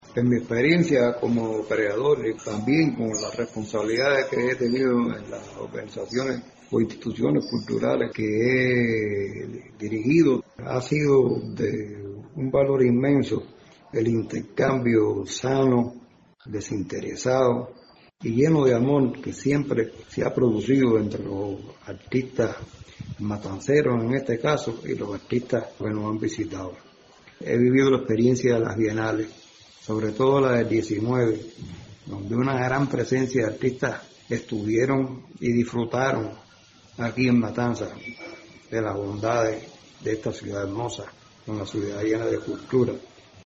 artesano